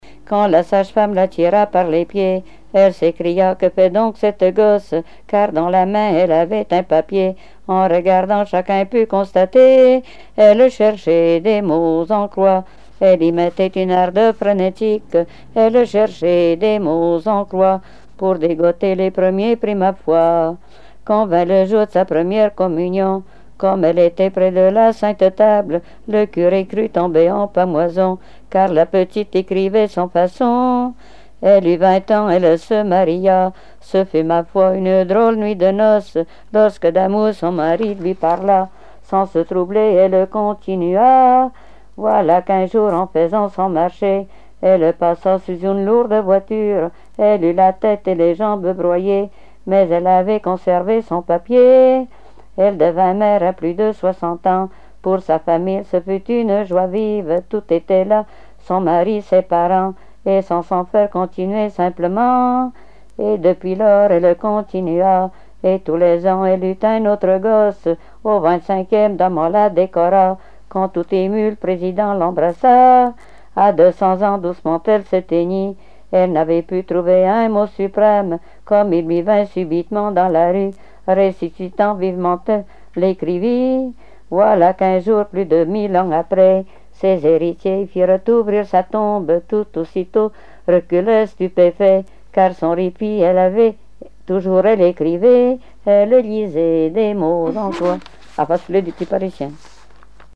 Répertoire de chansons traditionnelles et populaires
Catégorie Pièce musicale inédite